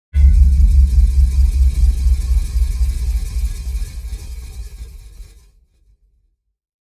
Explore the symphony of daily life with our Household Appliances Sound Effects.
Rusty-table-fan-spinning-4.mp3